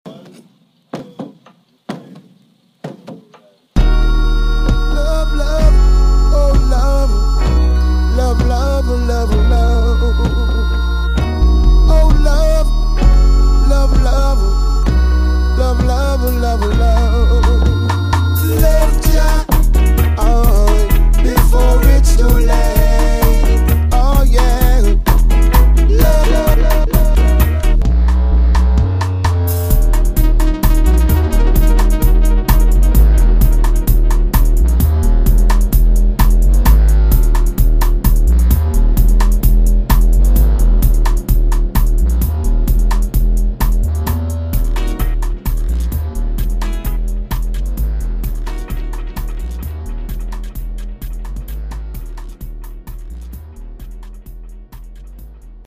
Sample of a remix